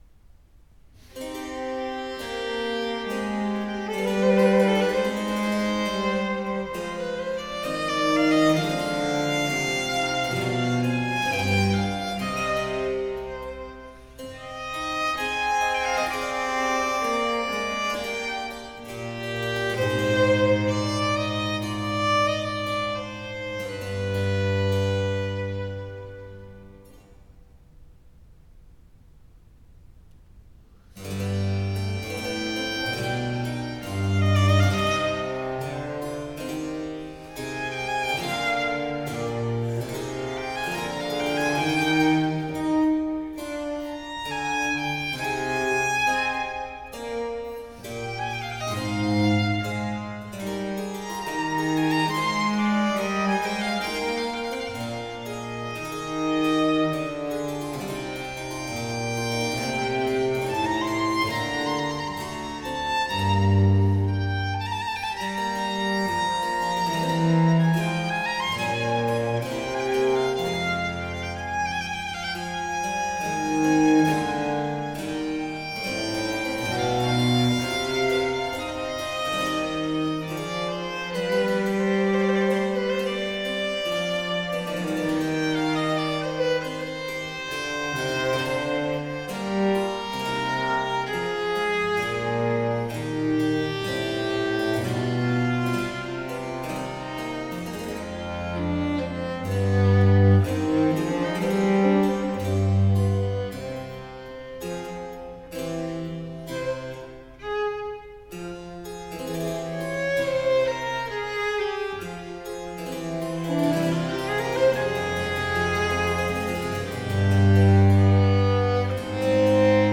Adagio